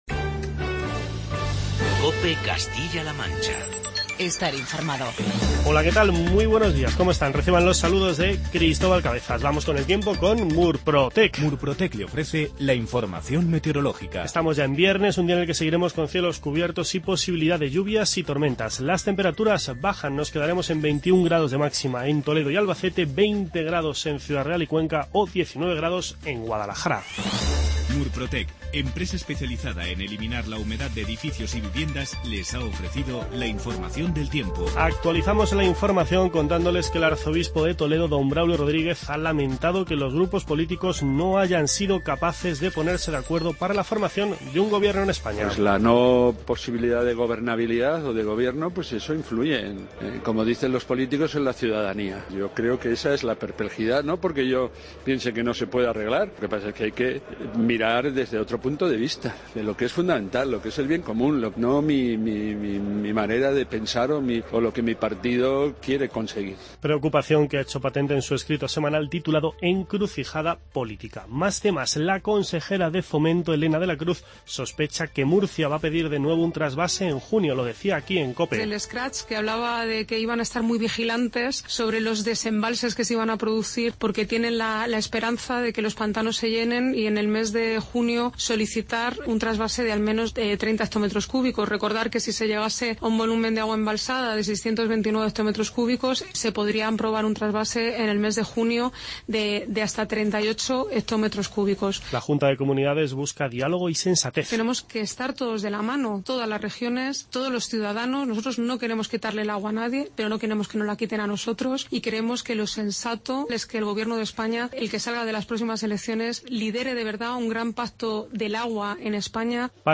Informativo regional
Escuchamos las palabras del arzobispo de Toledo, don Braulio Rodríguez.